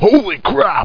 copalert.mp3